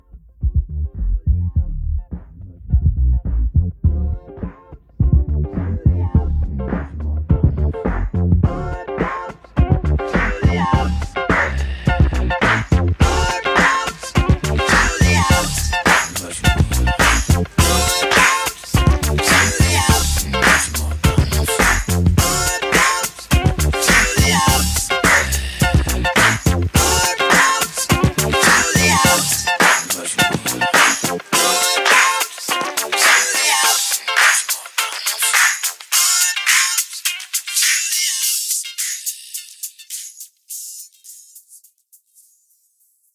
The FilterFade plug-in works for me.